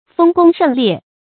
豐功盛烈 注音： ㄈㄥ ㄍㄨㄙ ㄕㄥˋ ㄌㄧㄝ ˋ 讀音讀法： 意思解釋： 猶言豐功偉績。巨大隆盛的功業。